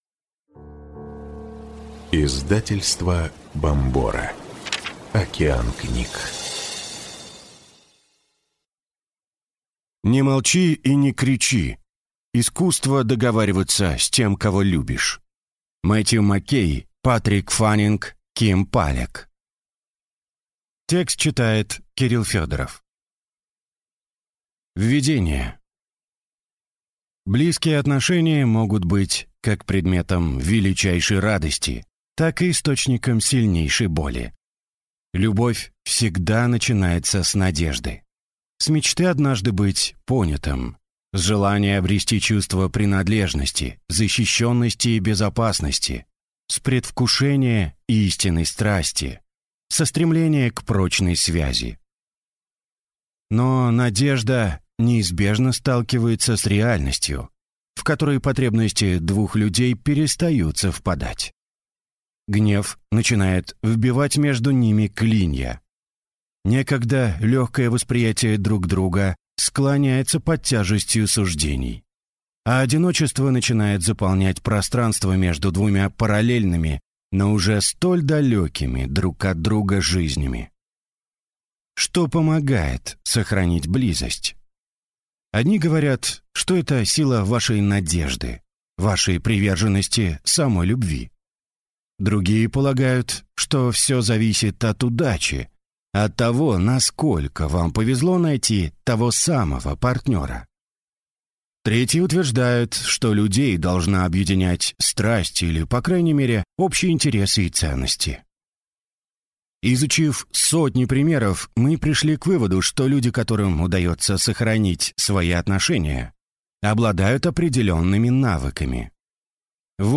Аудиокнига Не молчи и не кричи. Искусство договариваться с тем, кого любишь | Библиотека аудиокниг